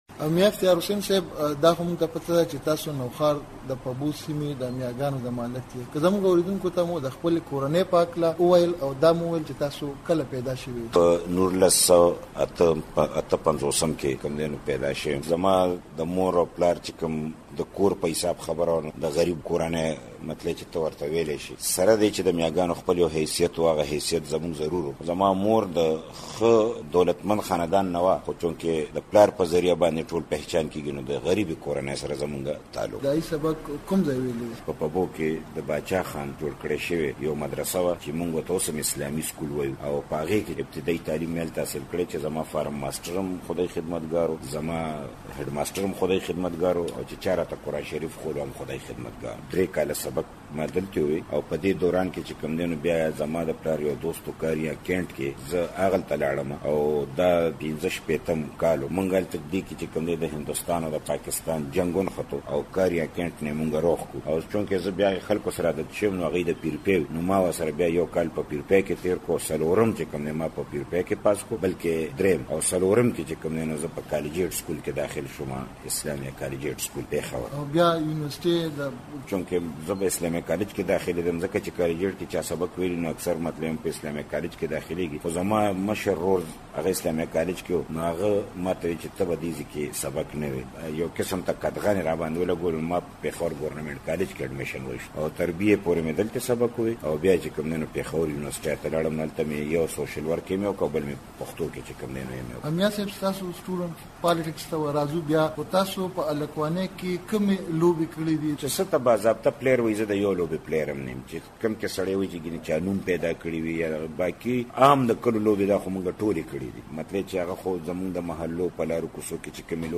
میاافتخار حسین د خیبر پښتونخوا د اطلاعاتو او کلتور پخوانی صوبايي وزیر او د عوامي نېشنل ګوند له مهمو مشرانو څخه دی. له نوموړي سره د مشال مرکه لرو.